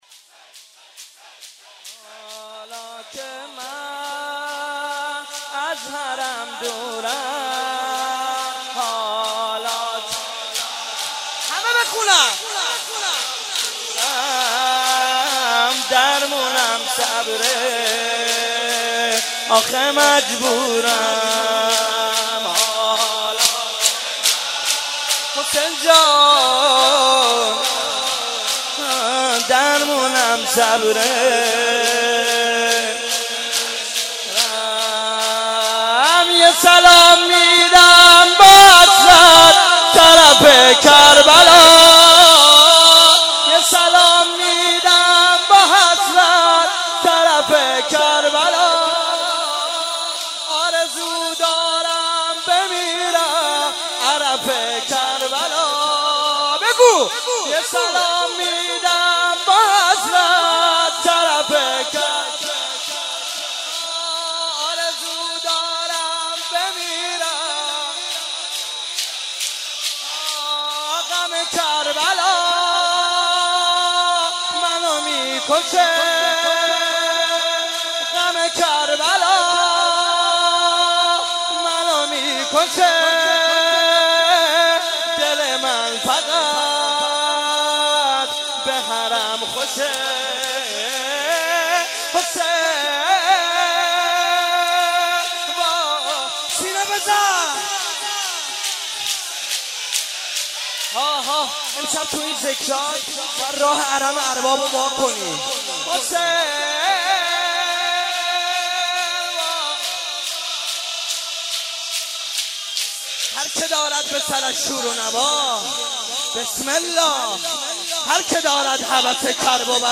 بخش اول - مناجات
بخش دوم - روضه
بخش سوم - مداحی